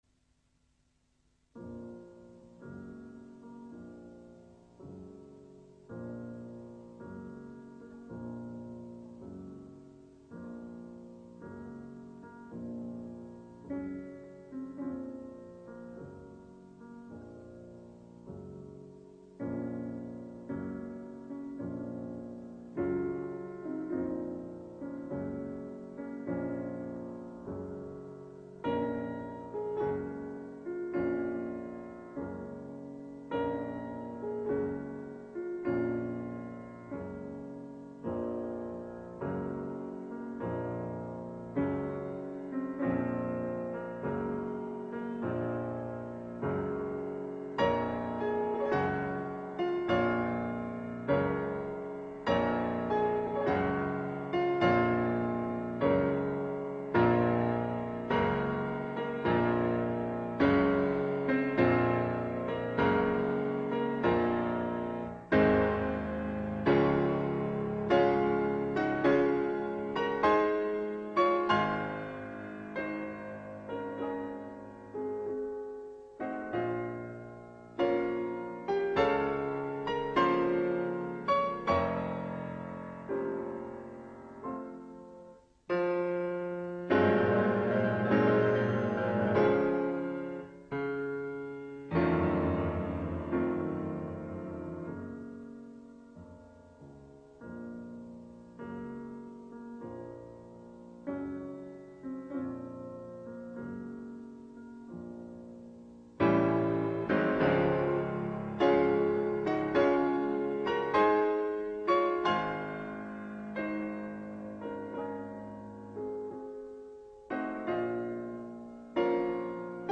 MUSIQUES CLASSIQUES